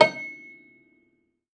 53w-pno13-C5.wav